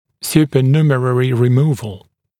[ˌs(j)uːpə’njuːmərərɪ rɪ’muːvl][ˌс(й)у:пэ’нйу:мэрэри ри’му:вл]удаление сверхкомплектного зуба